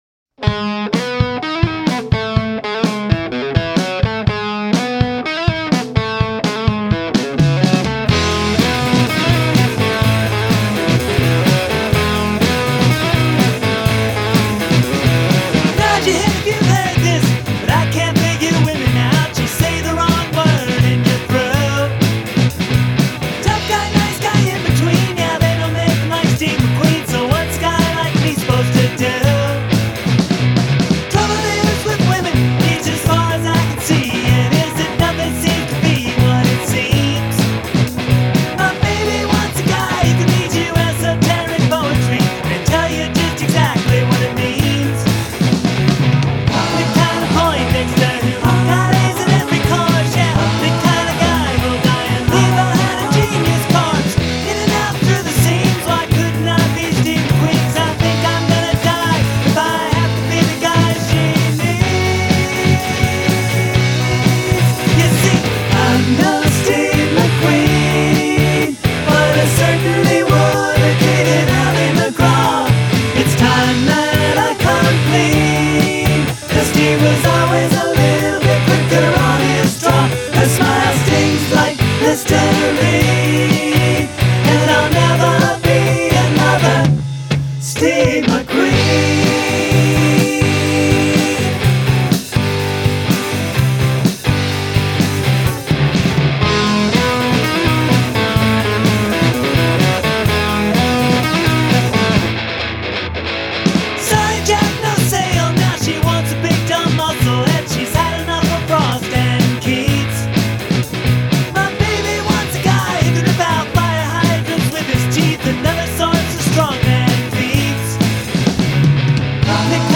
I got a new amp and recorded over an old drum track.